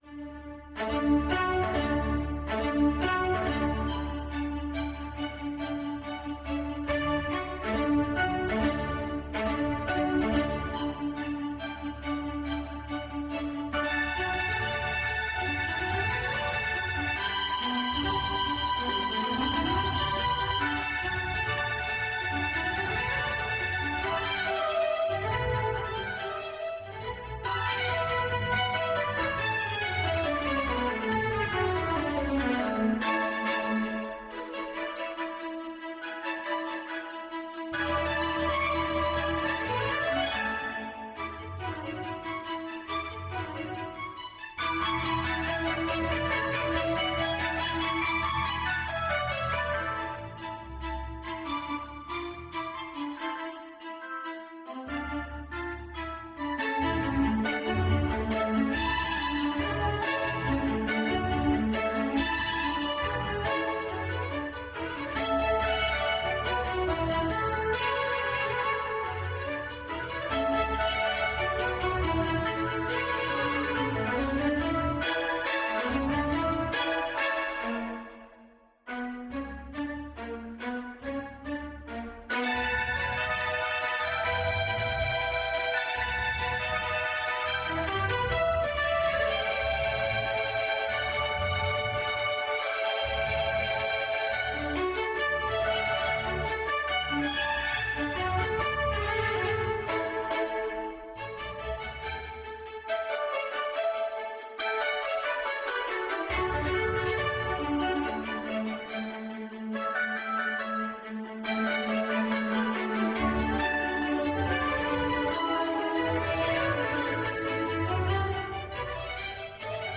musique classique